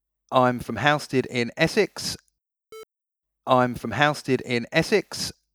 Is it possible to fix this distorted audio?
ISSUE: some of the sound is distorted.